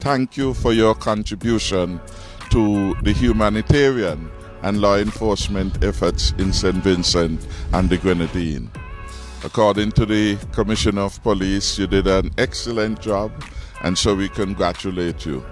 They were in St. Vincent and the Grenadines for three weeks assisting the local authorities maintain security and protect property on the main island, where the eruption of the La Soufrière Volcano forced thousands to evacuate. Prime Minister, Dr. Timothy Harris, as well as members of the RSCNPF and the SKNDF, met the group at the Robert Llewellyn Bradshaw International Airport and welcomed them home.
Prime Minster Harris had this to say: